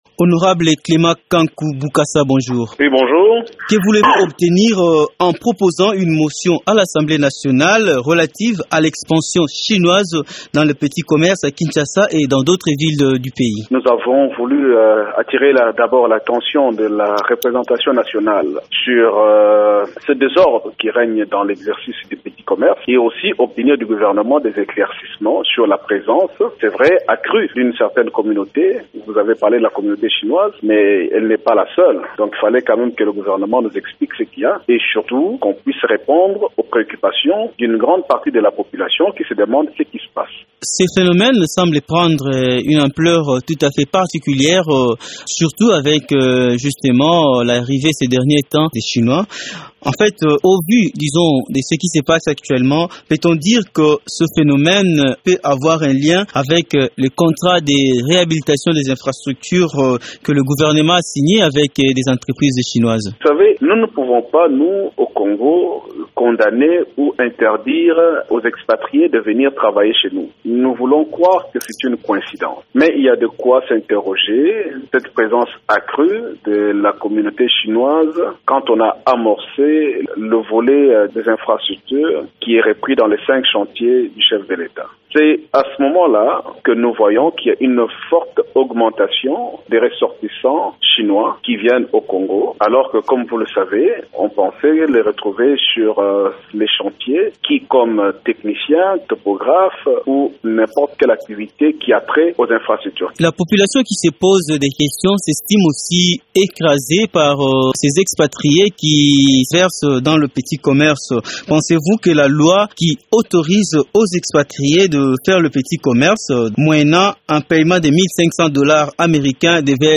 De plus en plus des expatriés, parmi lesquels les chinois se lancent dans les petits commerces à travers les villes de la RDC et ce au détriment des populations locales. Que dit la loi au sujet des petits commerces et que faut il faire pour réglémenter ce secteur, Honorable Clément Kanku Bukasa, initiateur d’une motion sur l’exercice de petits commerces par les expatriés répond dans cet entretien